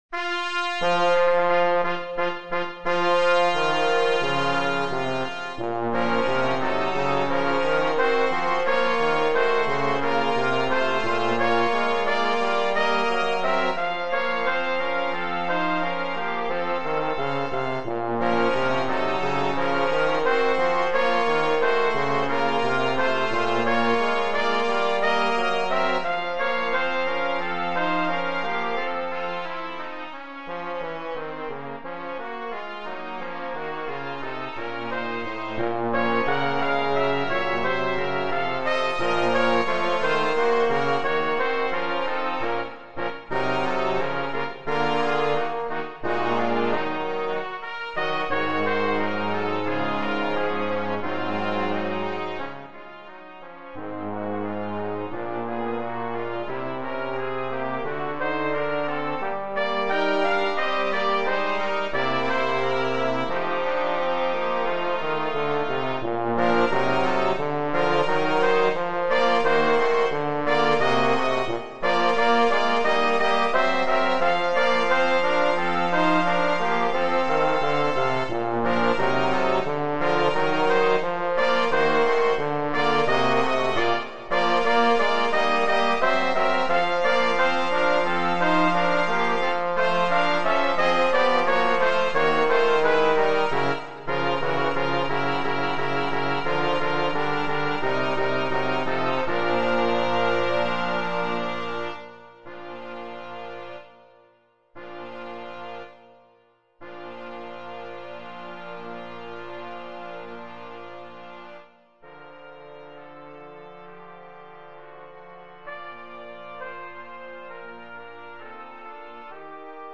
Per 2 trombe, trombone 1 (o cr.) e trombone 2